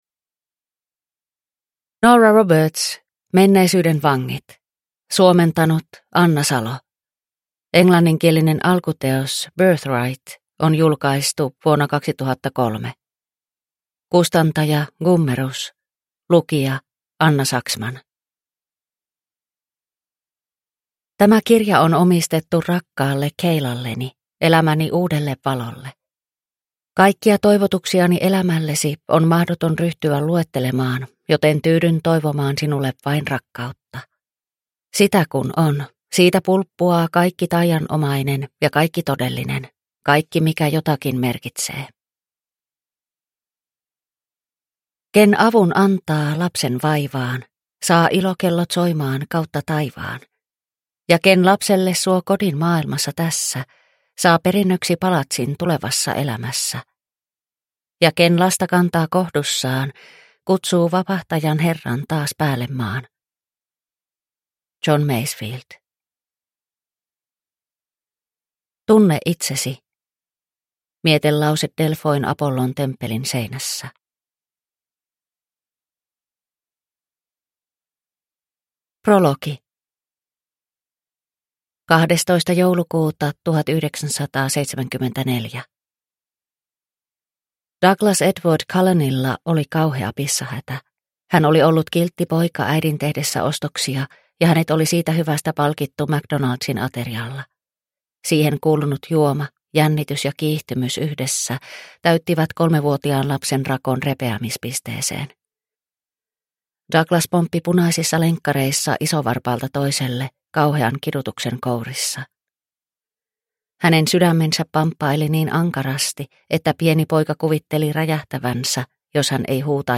Menneisyyden vangit – Ljudbok – Laddas ner